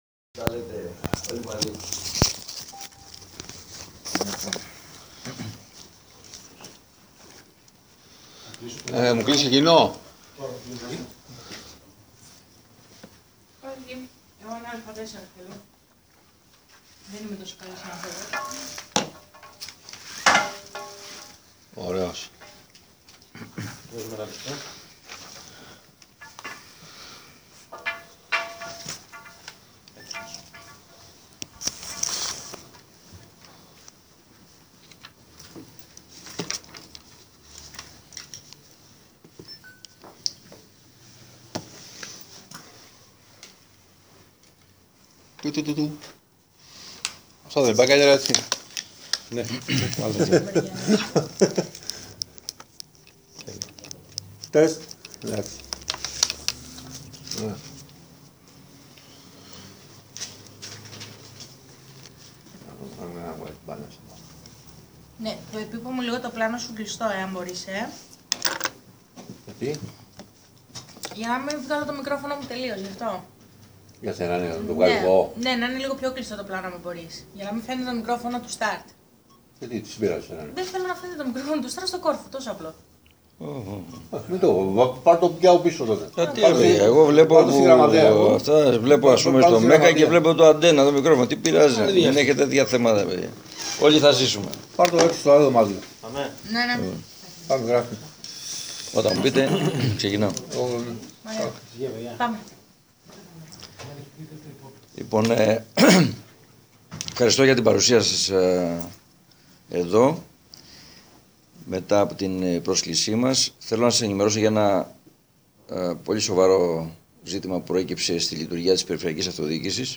Συνέντευξη του Περιφερειάρχη Θ. Γαλιατσάτου: ΣΥΝΕΝΤΕΥΞΗ_ΠΕΡΙΦΕΡΕΙΑΡΧΗΣ ΙΟΝΙΩΝ ΝΗΣΩΝ_ΓΑΛΙΑΤΣΑΤΟΣ_24.10.2014